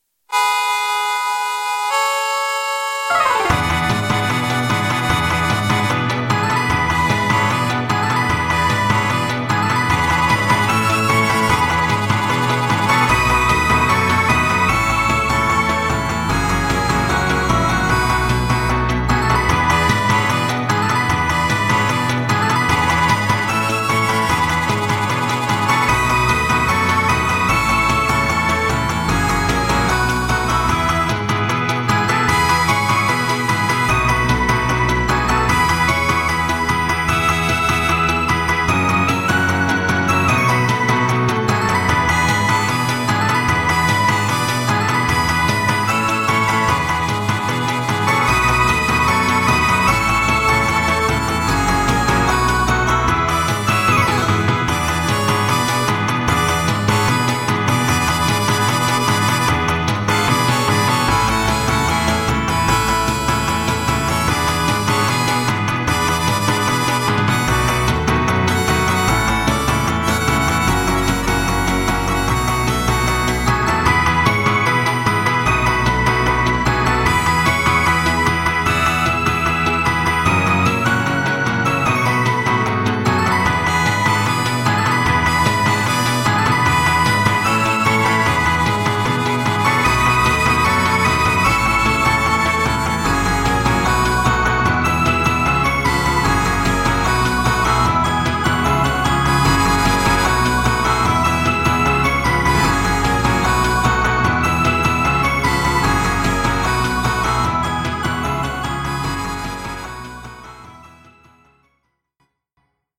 Исправил ошибки в звучании проигрыша.